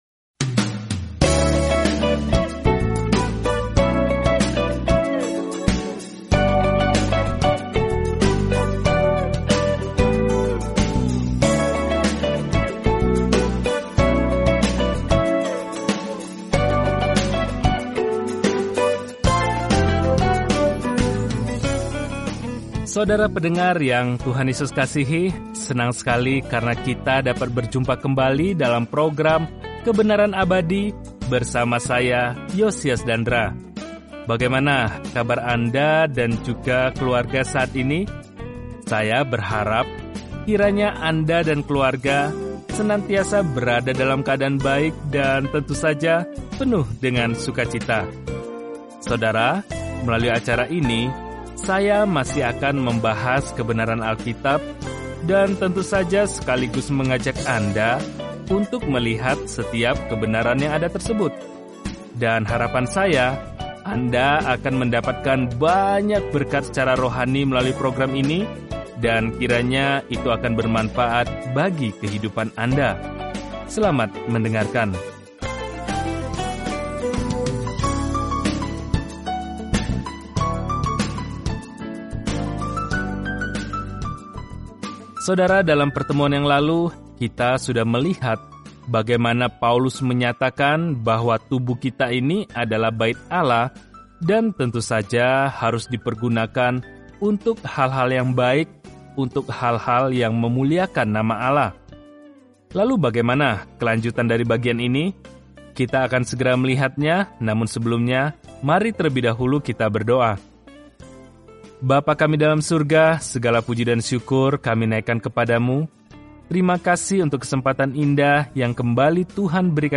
Apakah topik tersebut dibahas dalam surat pertama kepada jemaat di Korintus, memberikan perhatian praktis dan koreksi terhadap permasalahan yang dihadapi kaum muda Kristen. Telusuri 1 Korintus setiap hari sambil mendengarkan pelajaran audio dan membaca ayat-ayat tertentu dari firman Tuhan.